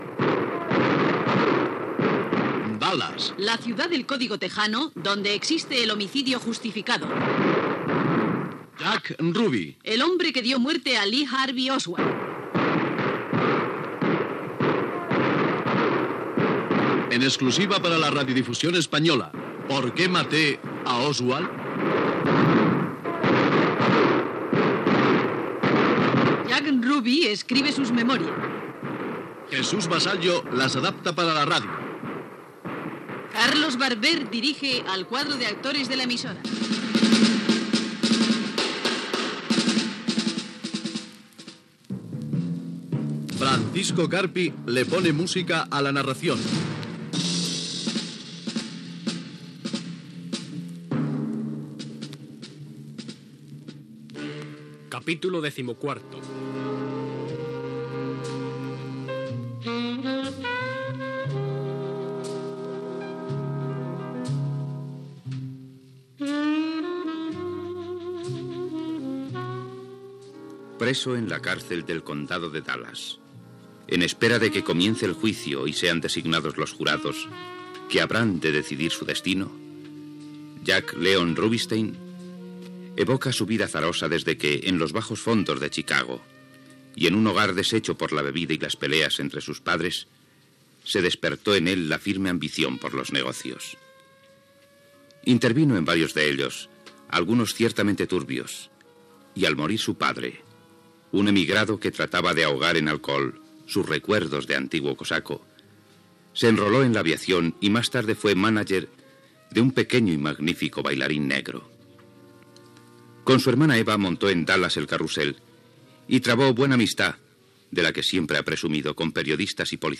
Capítol 14 de la sèrie. Careta del programa, el narrador explica la vida de Jack Ruby, qui matà a Lee Harvey Oswald acusat de l'assassinat del president dels EE.UU. John Fitzgerald Kennedy a Dallas
Ficció